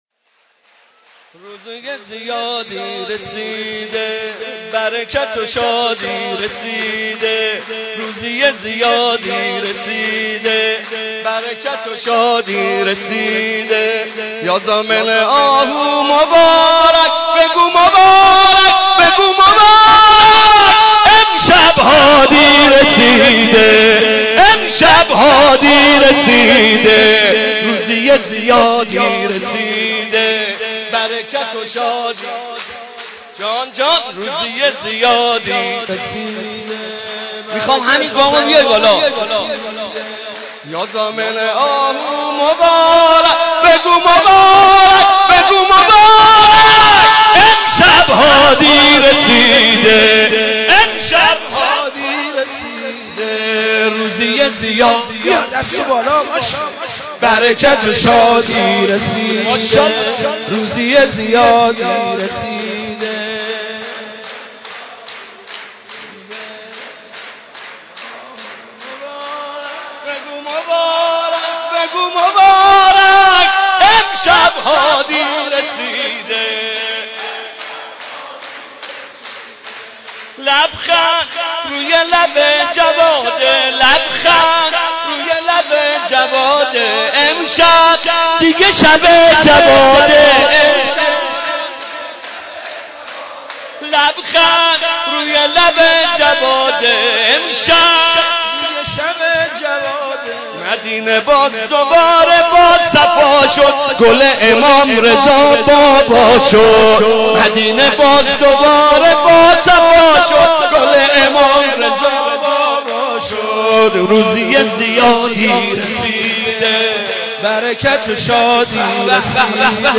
صوت/مدیحه سرایی حاج محمد رضا طاهری بمناسبت میلاد امام هادی(ع) - تسنیم
صوت مدیحه سرایی حاج محمد رضا طاهری بمناسبت میلاد فرخنده امام علی النقی (علیه السلام) منتشر می شود.